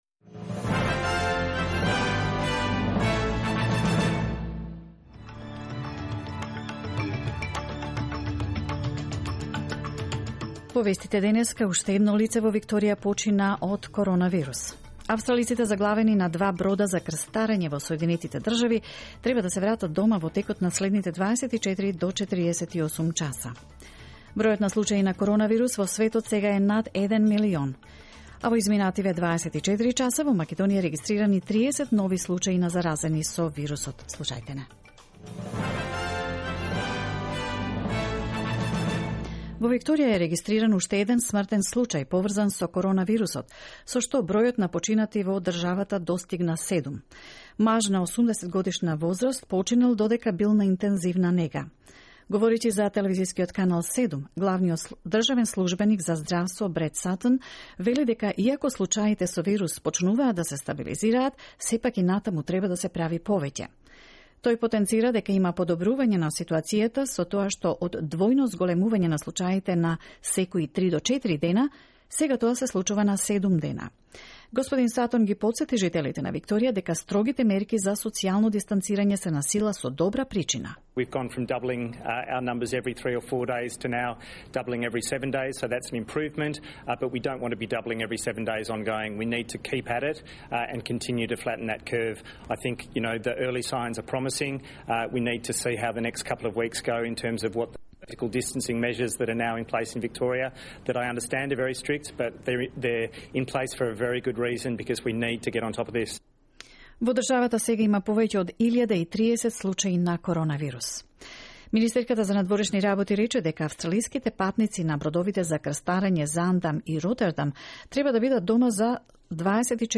News in Macedonian, 3 April 2020